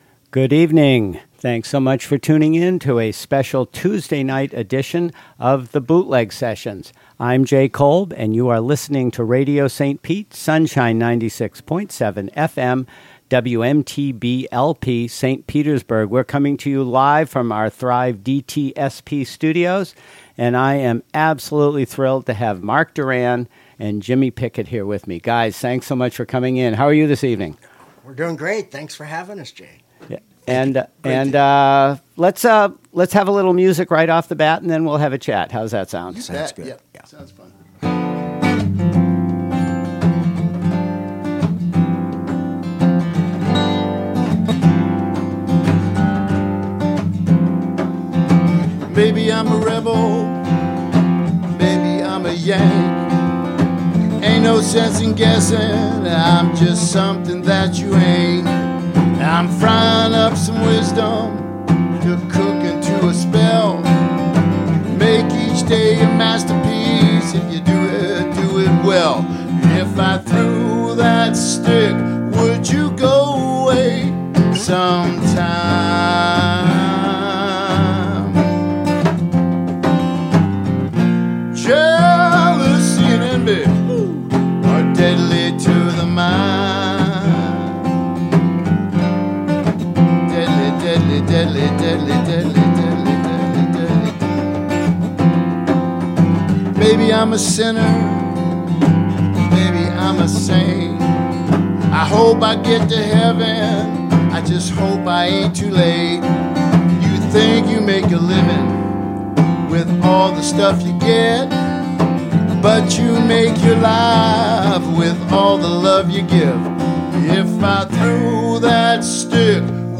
Broadcast live from the RadioStPete, Sunshine 96.7FM studio, located at Thrive DTSP.